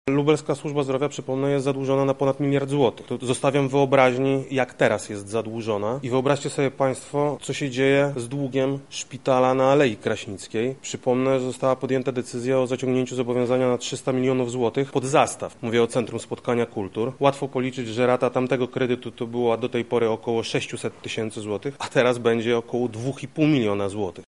-mówi Krzysztof Komorski, radny sejmiku województwa lubelskiego z Platformy Obywatelskiej